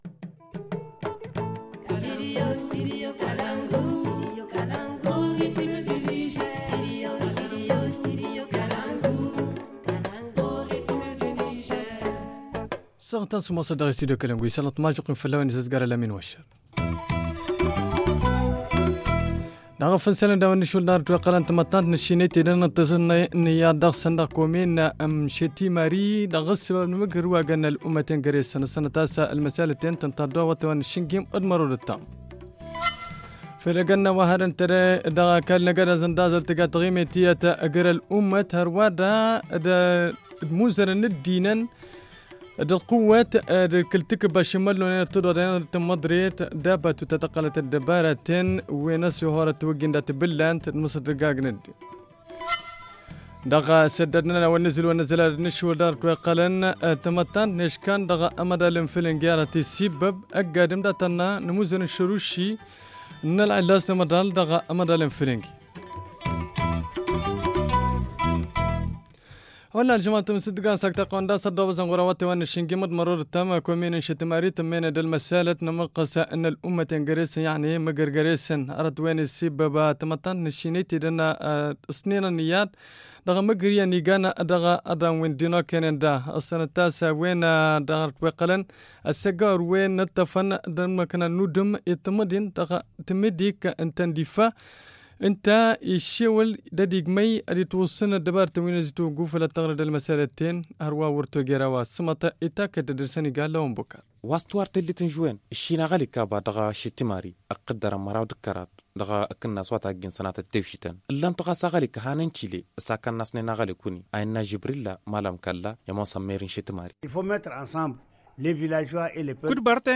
Le journal du 14 juin 2019 - Studio Kalangou - Au rythme du Niger